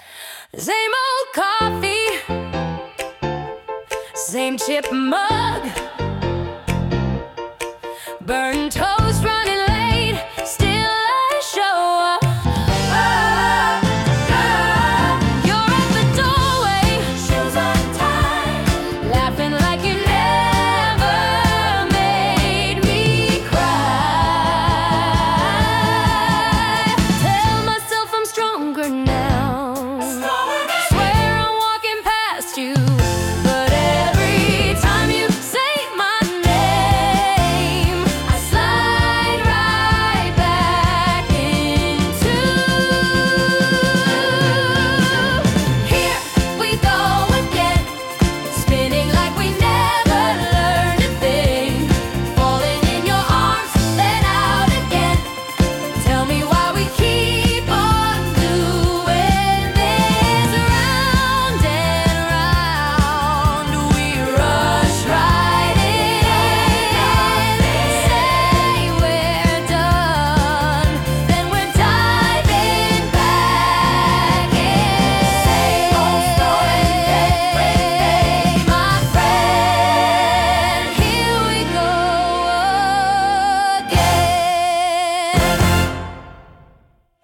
観客も思わず手拍子をしたくなるようなアップビートでハッピーな曲調は、笑顔と魅力いっぱいの演技にぴったりです。